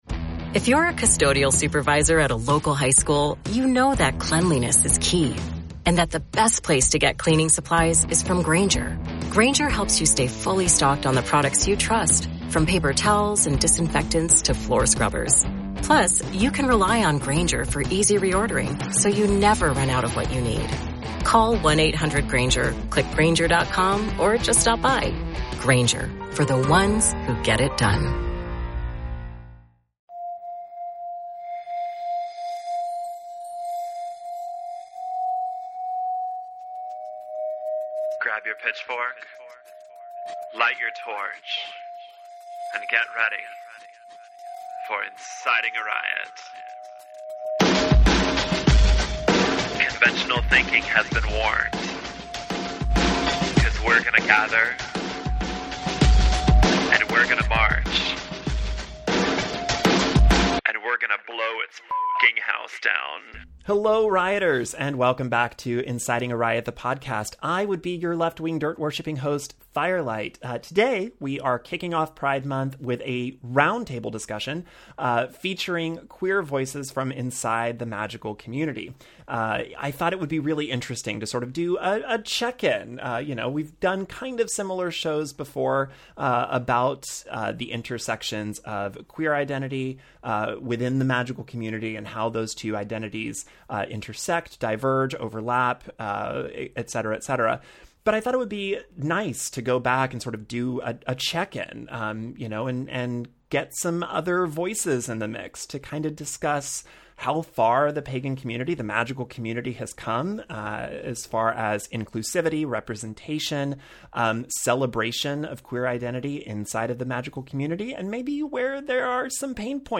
Episode 170 is a roundtable with a diverse group of queer practitioners from around the world representing multiple facets of the magical community.